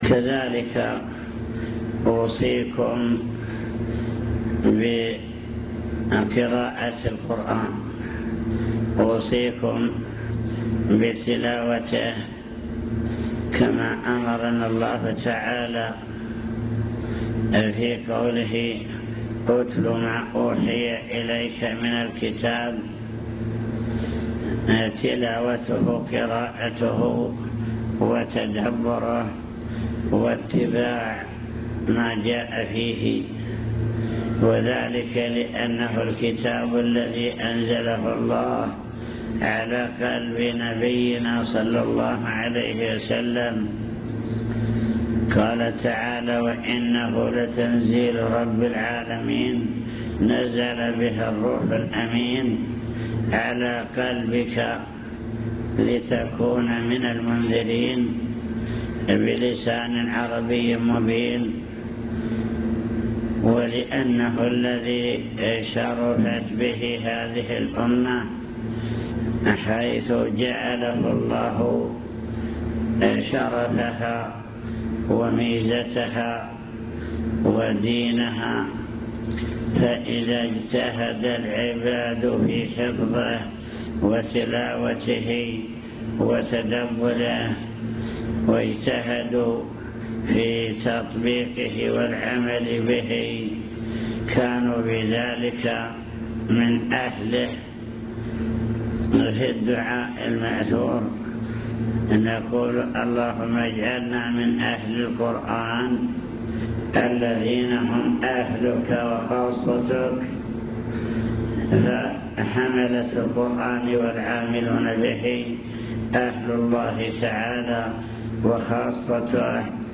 المكتبة الصوتية  تسجيلات - لقاءات  كلمة للمعلمين وطلاب التحفيظ وصايا من الشيخ